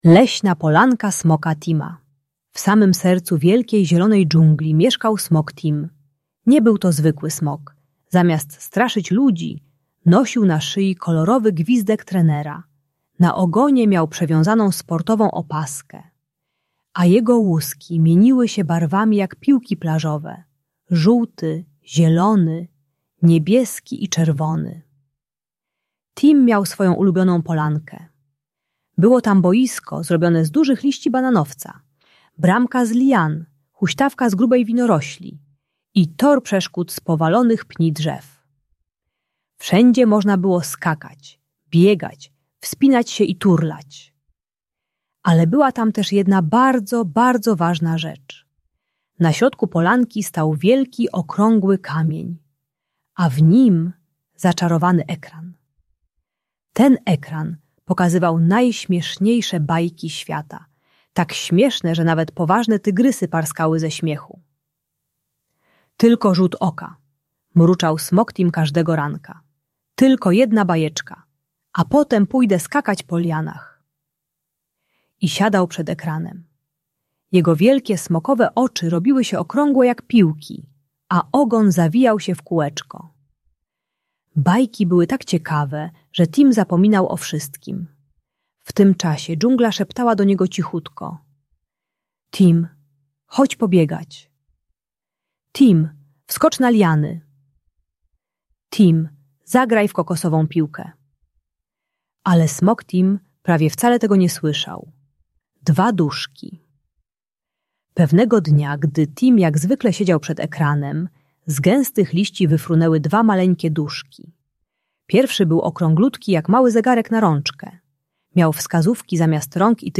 Bajka dla dziecka które za dużo ogląda tablet i telewizję, przeznaczona dla przedszkolaków 4-6 lat. Audiobajka o uzależnieniu od ekranów uczy techniki "STOP-ekran" i pomaga ustalić limit czasu przed telewizorem.